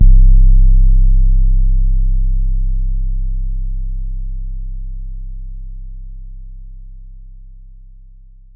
TC 808 6.wav